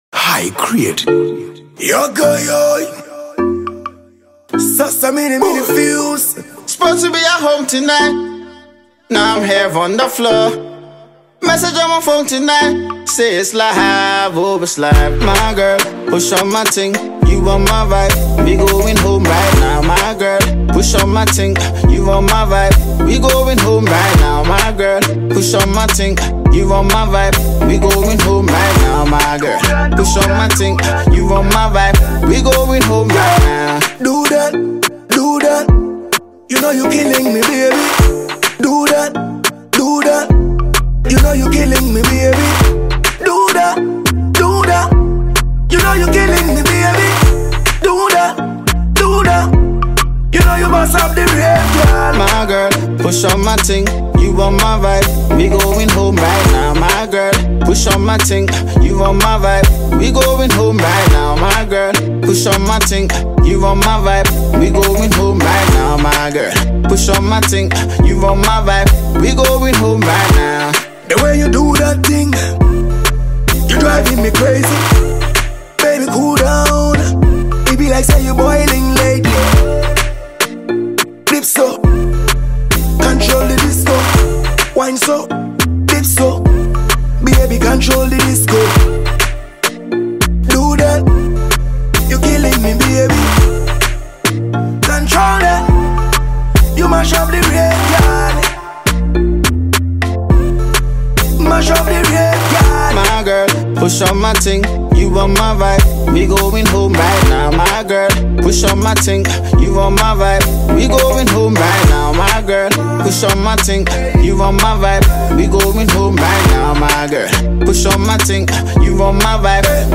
Award-winning Ghanaian Reggae and Dancehall artiste
The groovy dancehall tune